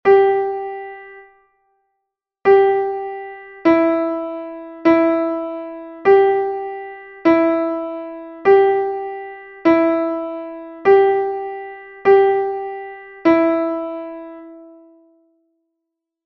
Listen to the sound file and write the notes you hear (E or G for notes and R for rests).
e_g_12_notes_and_rests..mp3